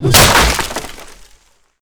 Wood_Axe.ogg